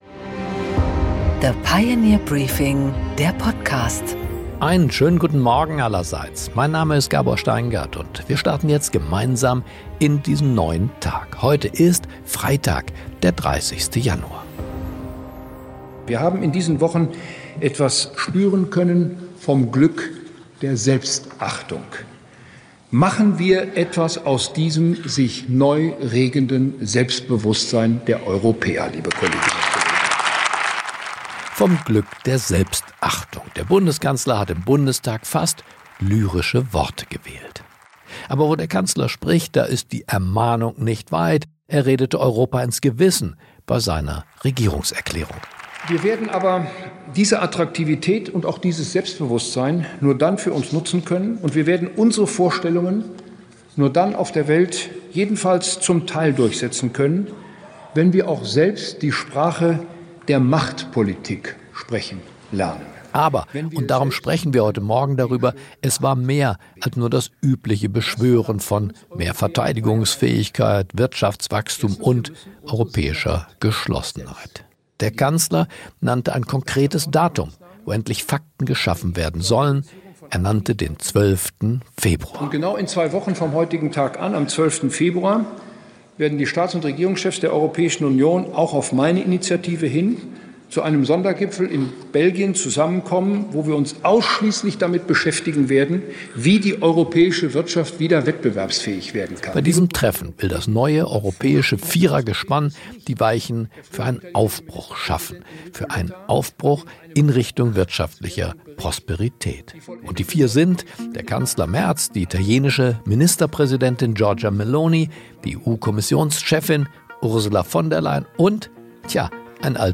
Gabor Steingart präsentiert das Pioneer Briefing.
Christian Sewing, Deutsche‑Bank‑CEO, erklärt im Gespräch mit Gabor Steingart, wie das Institut unter seiner Führung zum Rekordgewinn von 9,7 Milliarden Euro Vorsteuer kam – und wie er die wirtschaftliche Lage Deutschlands einschätzt.